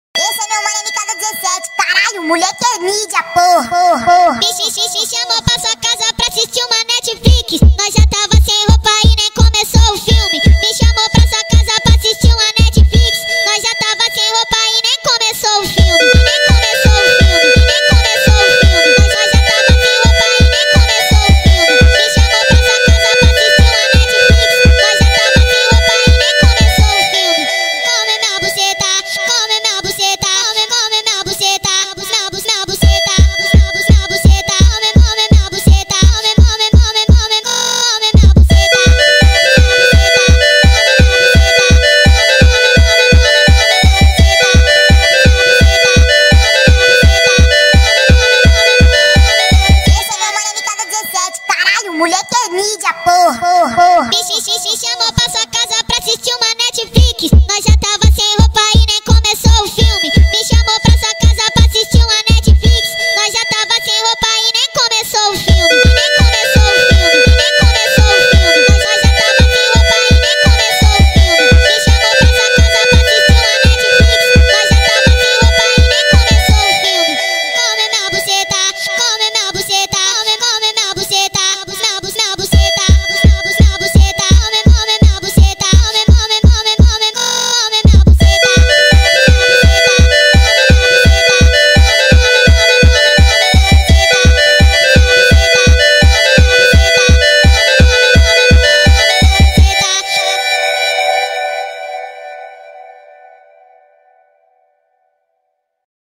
دانلود فانک ترند اینستاگرام با ریتم تند
فانک